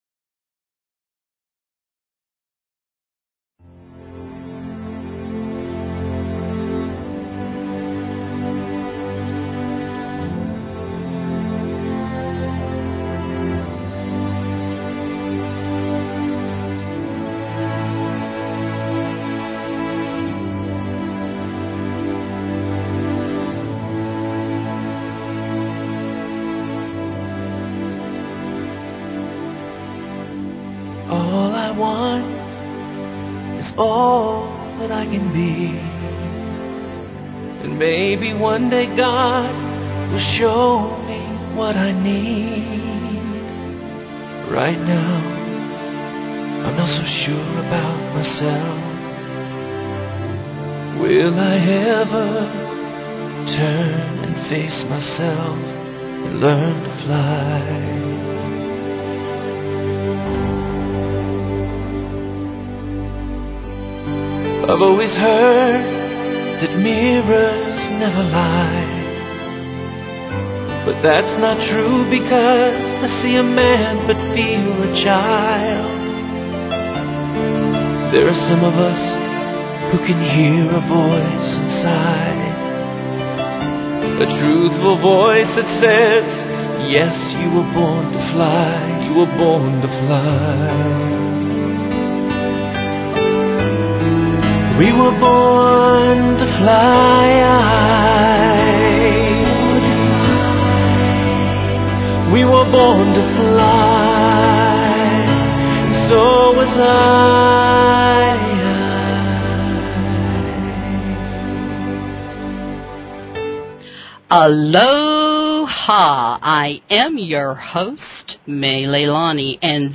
Talk Show Episode, Audio Podcast, Universal_Spiritual_Connection and Courtesy of BBS Radio on , show guests , about , categorized as